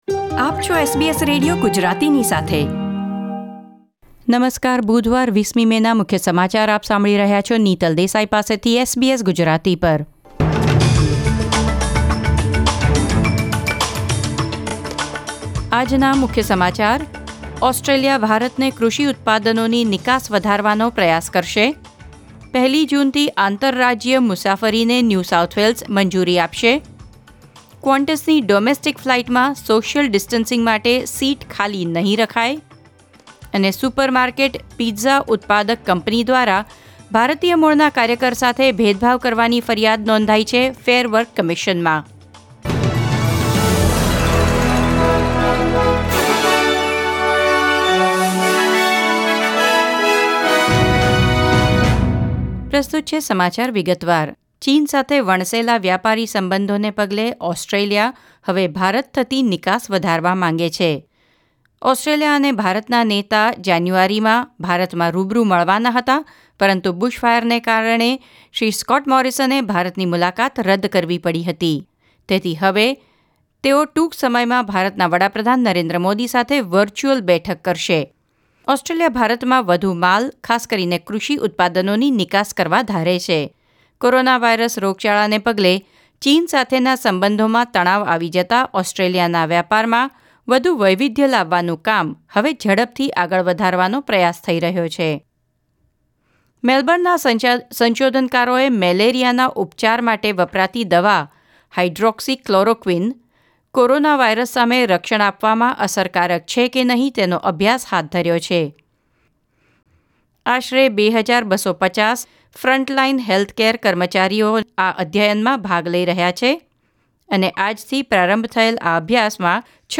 SBS Gujarati News Bulletin 20 May 2020